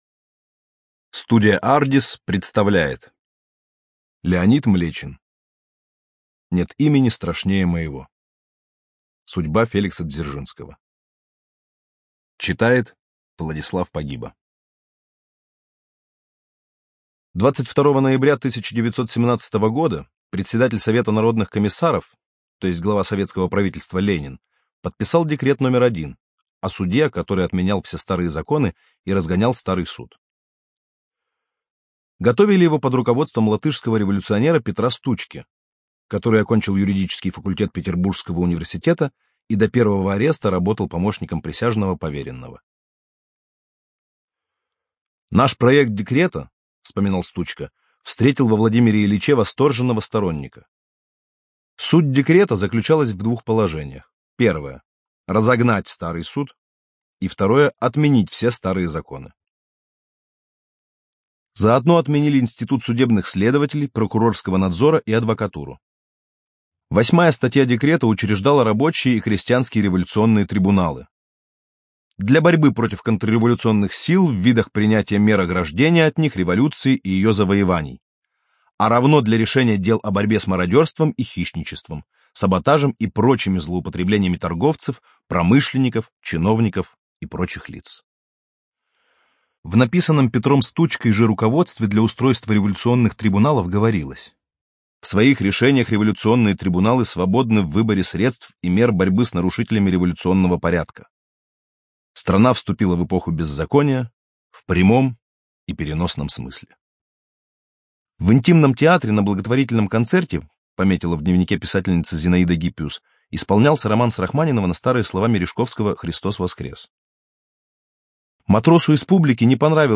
ЖанрБиографии и мемуары
Студия звукозаписиАрдис